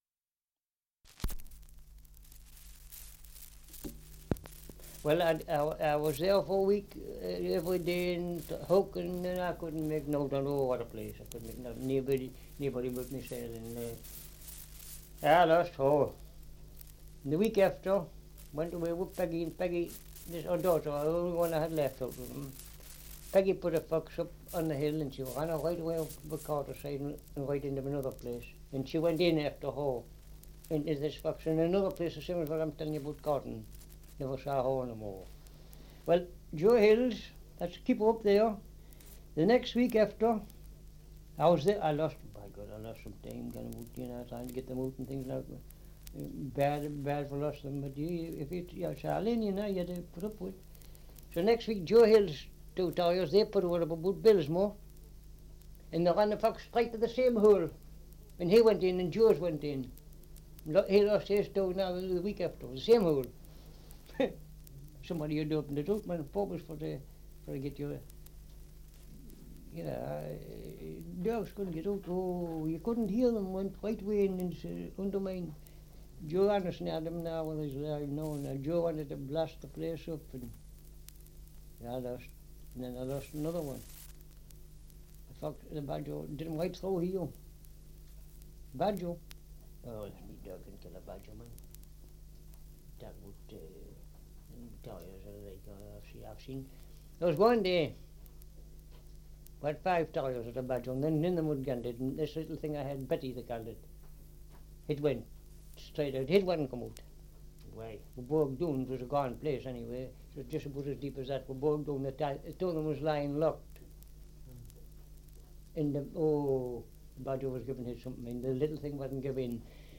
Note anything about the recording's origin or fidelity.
Survey of English Dialects recording in Thropton, Northumberland 78 r.p.m., cellulose nitrate on aluminium